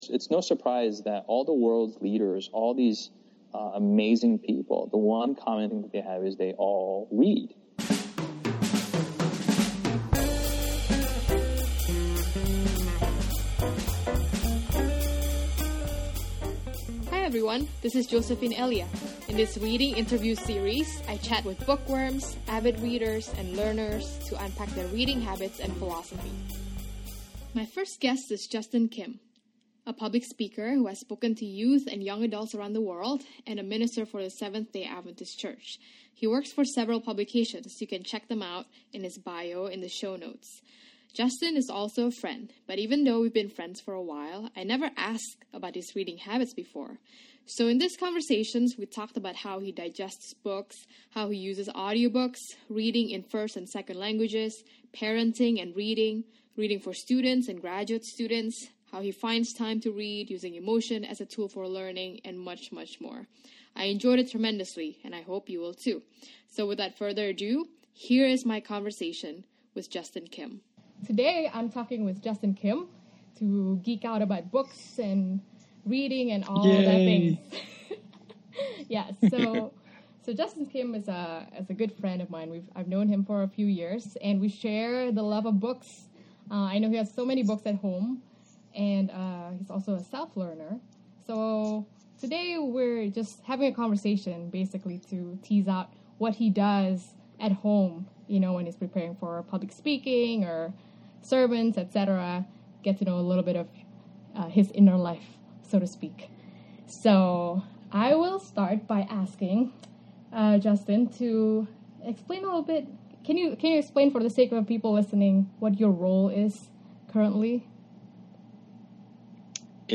Welcome to the inaugural episode of the Reading Interview Series, where I chat with bookworms, avid readers and learners, to unpack their reading habits and philosophy.